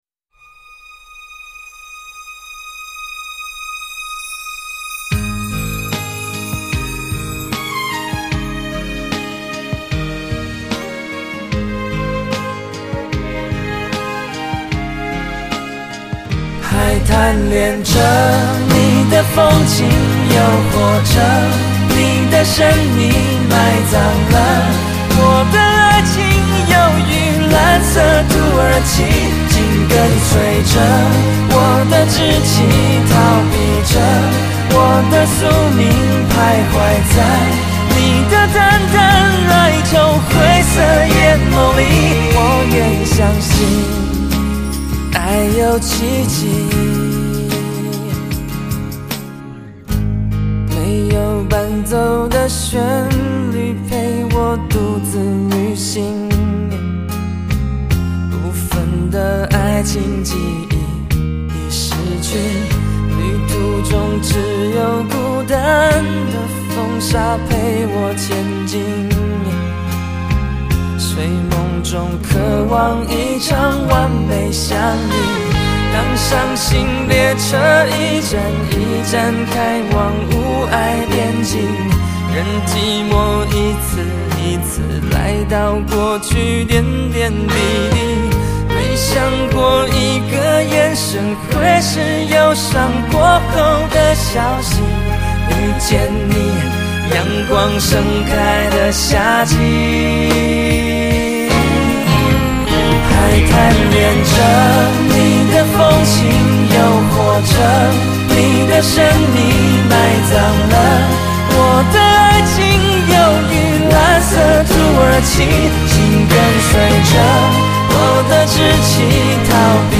突破性采用了R&B曲风的新尝试